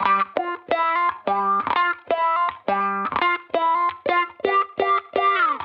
Index of /musicradar/sampled-funk-soul-samples/85bpm/Guitar
SSF_StratGuitarProc1_85G.wav